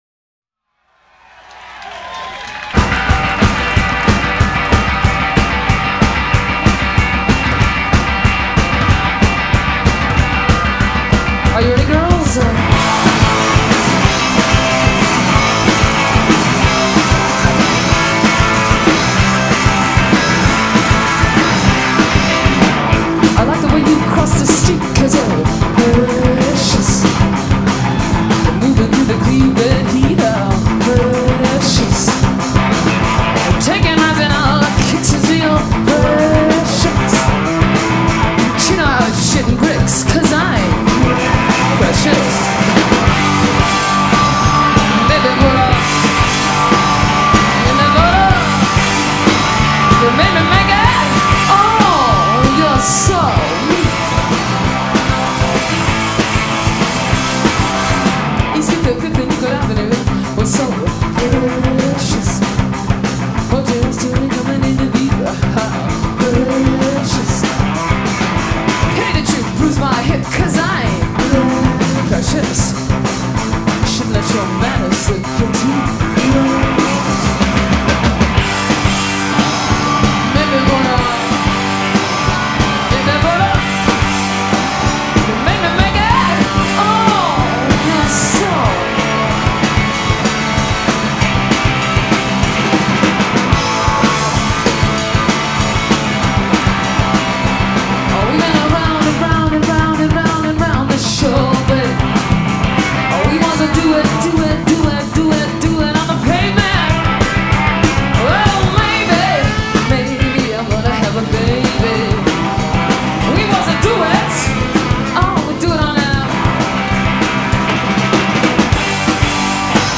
in Boston at the Paradise Theatre March 1980.